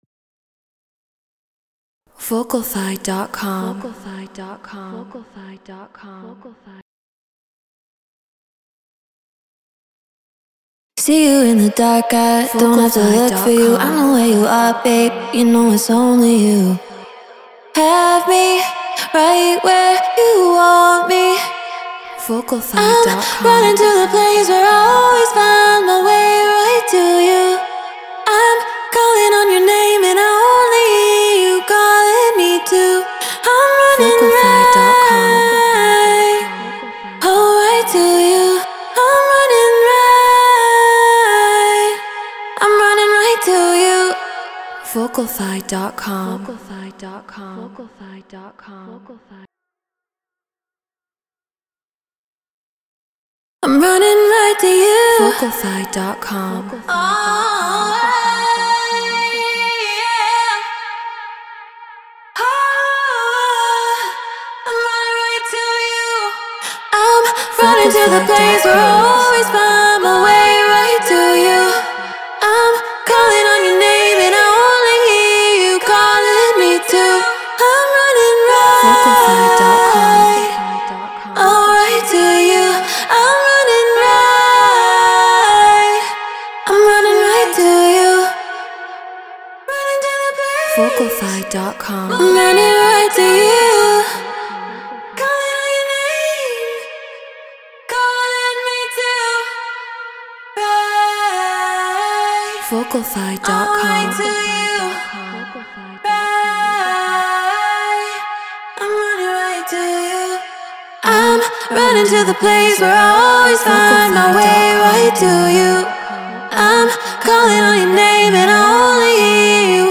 Drum & Bass 174 BPM D#min